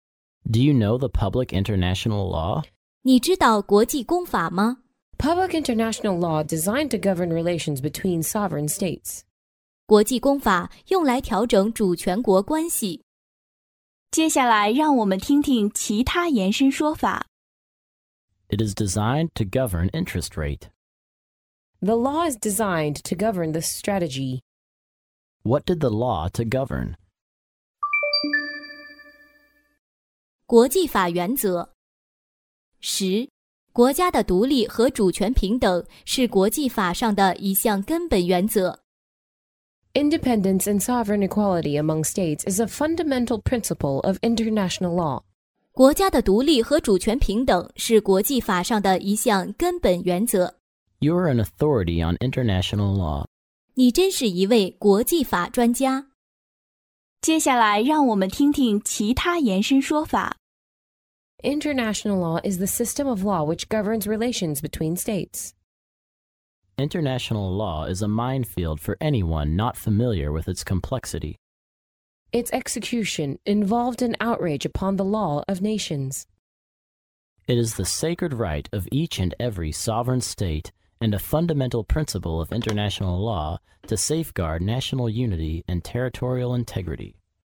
在线英语听力室法律英语就该这么说 第119期:国际公法用来调整主权国关系的听力文件下载,《法律英语就该这么说》栏目收录各种特定情境中的常用法律英语。真人发音的朗读版帮助网友熟读熟记，在工作中举一反三，游刃有余。